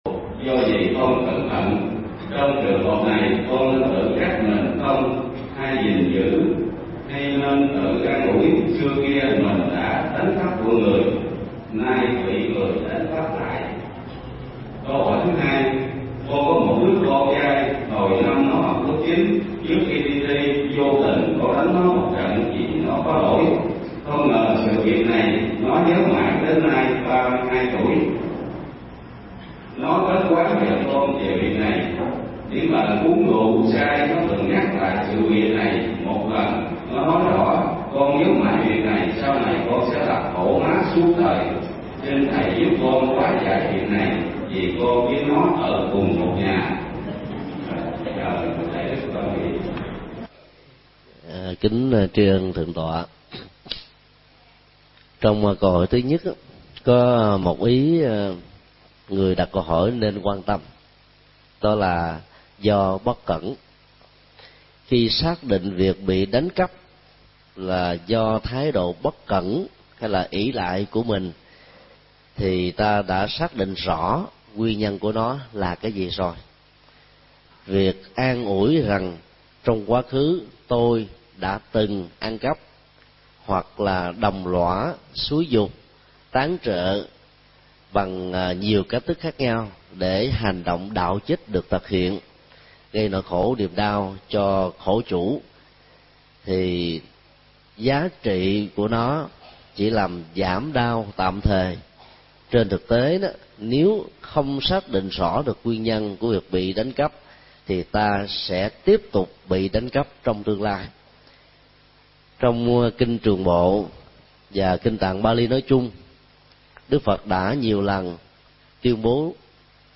Nghe mp3 Vấn đáp: Truy nguyên sự việc về quá khứ, vượt qua tâm lý bị hăm họa – Thầy Thích Nhật Từ